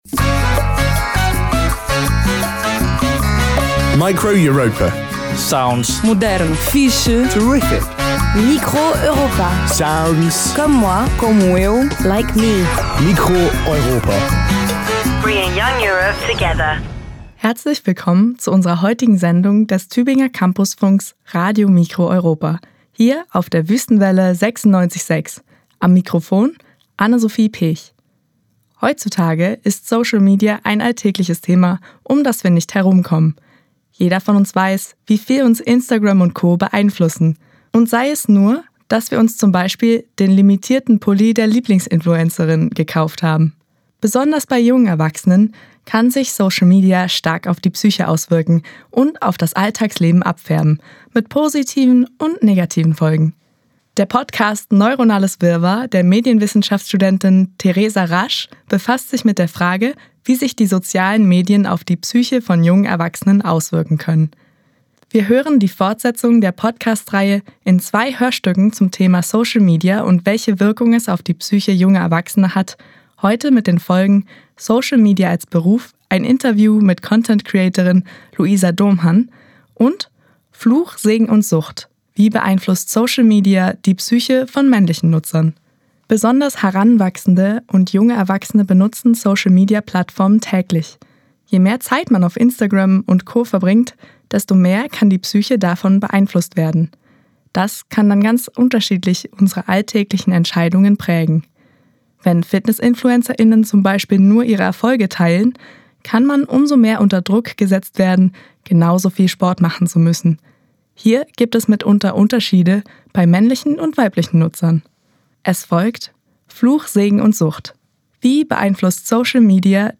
Vier Student*innen teilen ihre Erfahrungen mit Social-Media-Plattformen wie Instagram.